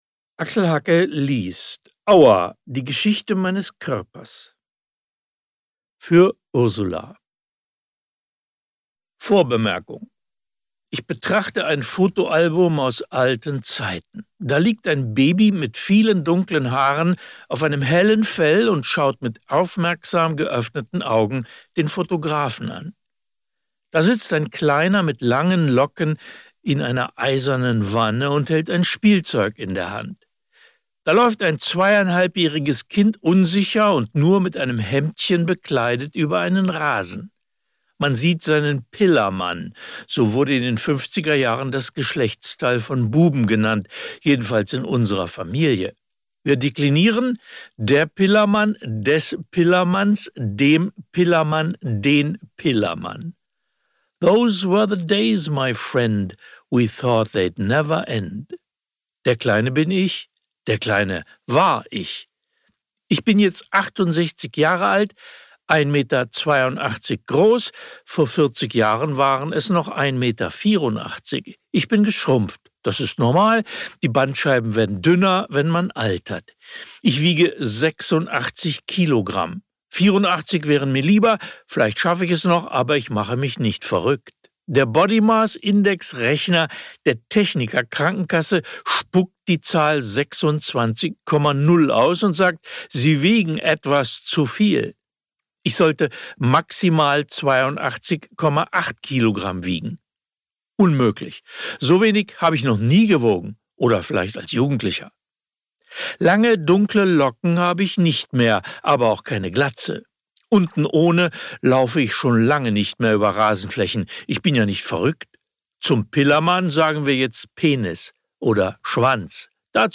Ungekürzte Autorenlesung mit Axel Hacke (1 mp3-CD)
Axel Hacke (Sprecher)